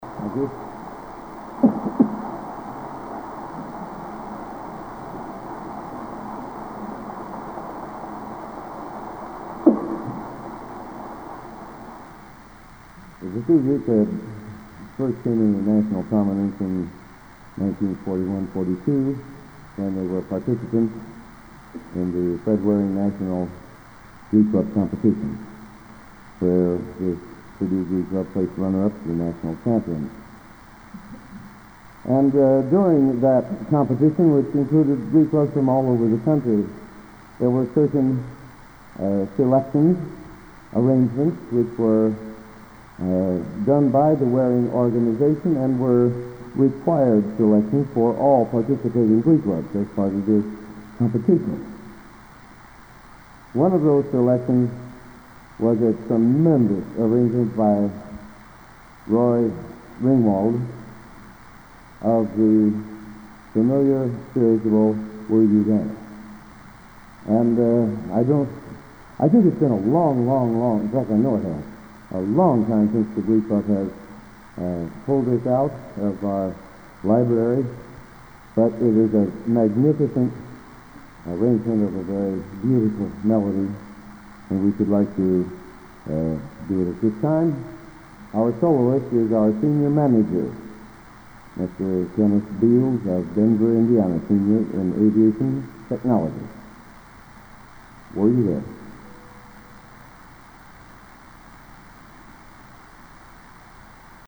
Collection: Broadway Methodist, 1980
Genre: | Type: Director intros, emceeing